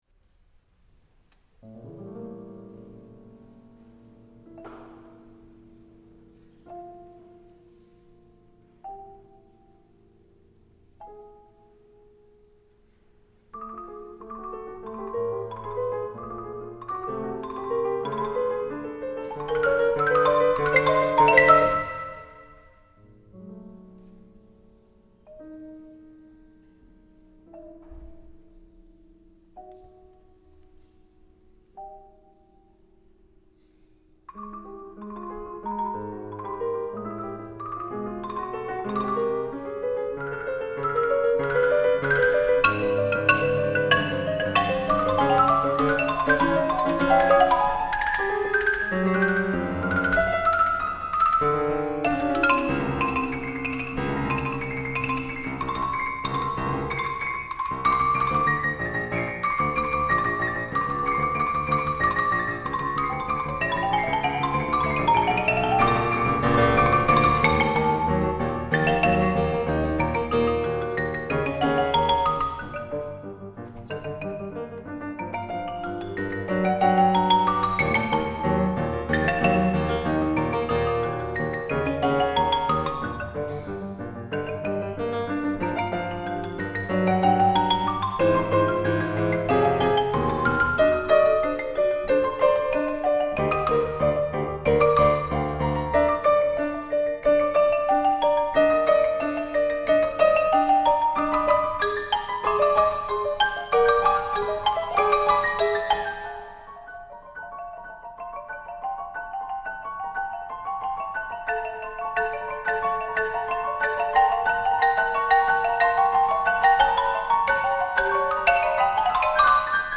MECHANIZMO (Marimba and Piano) (2015) Program Note: Mechanizmo is a sonata for marimba and piano, percussive doppelgängers, and in this piece, a composite keyboard of terrific range and virtuosity. I wanted to explore the technical and timbral qualities of this incredibly complex mechanism, whereby one instrument hammers strings while the other strikes wood.
Rather than relying on tonality in the traditional sense, I utilized contrasts of material, style, and musical parameters like density and duration to enact the dialectical struggle.